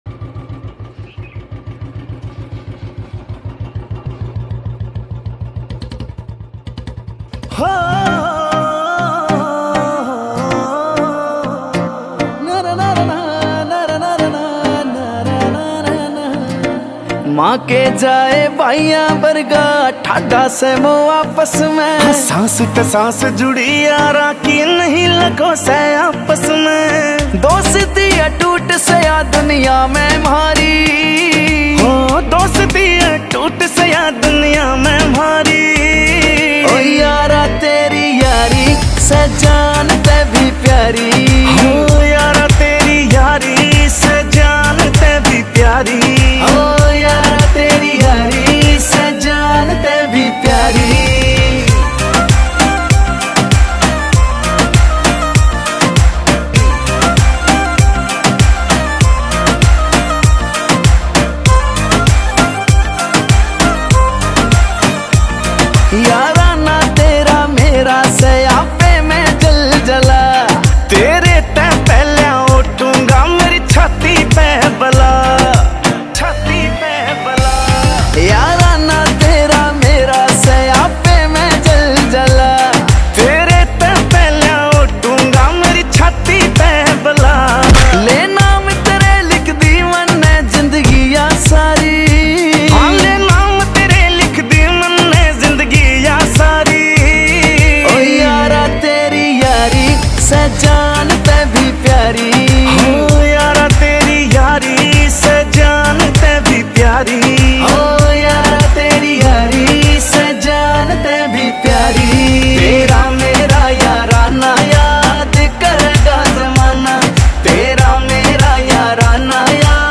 Releted Files Of Haryanvi